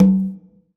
CONGA 2.wav